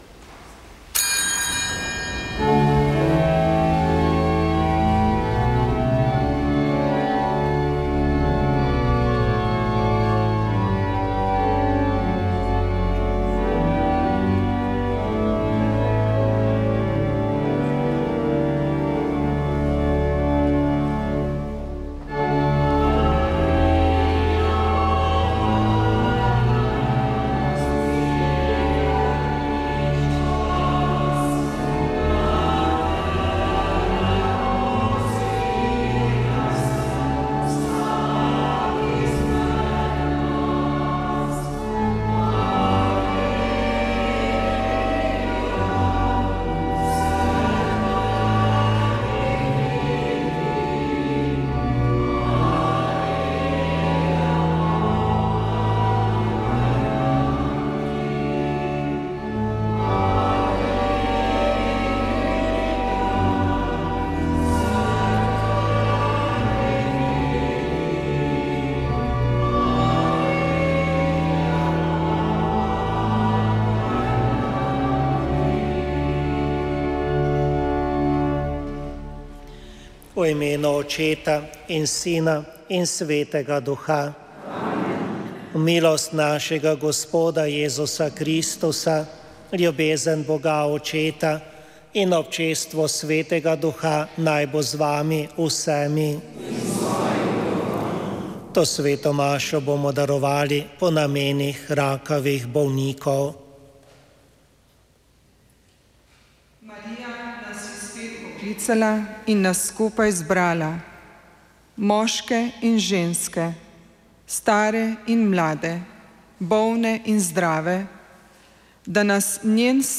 Sveta maša iz župnijske cerkve Povišanja Svetega Križa v Vinici v Beli krajini
Sveto mašo smo prenašali iz župnijske cerkve Povišanja Svetega Križa v Vinici v Beli krajini.